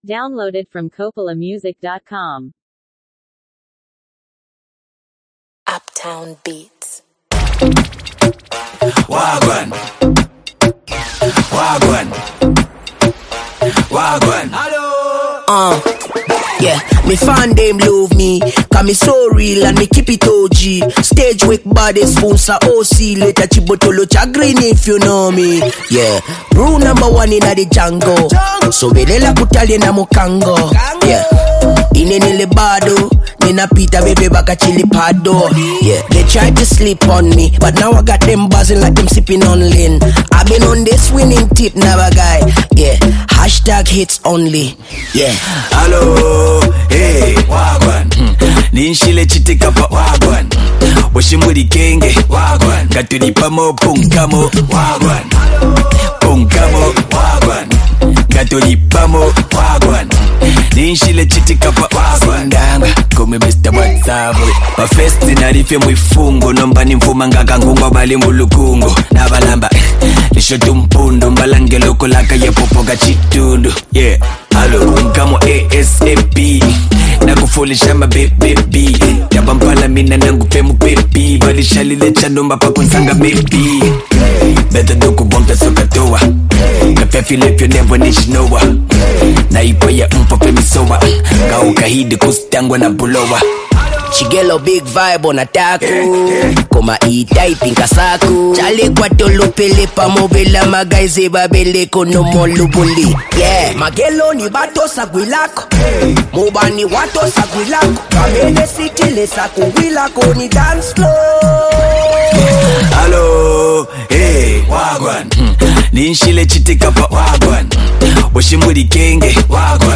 making the track feel modern and upbeat